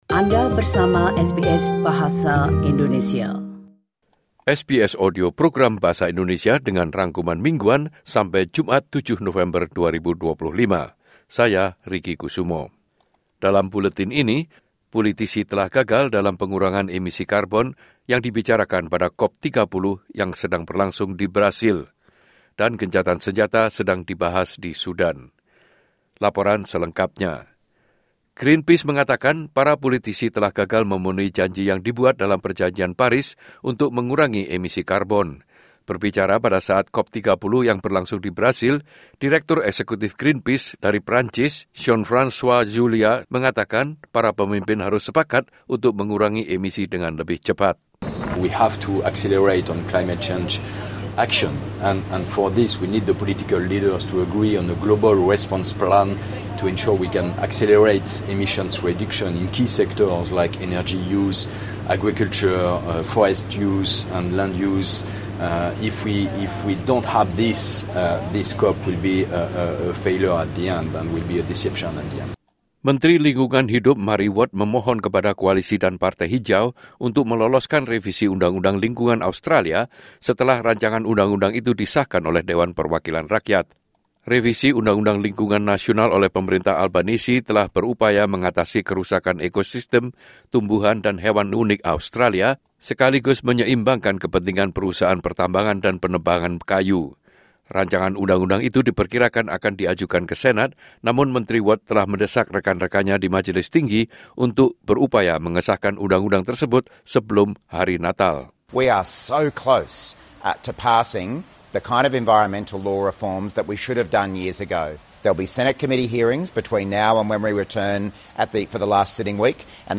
Weekly News Summary SBS Audio Program Bahasa Indonesia - Friday 7 November 2025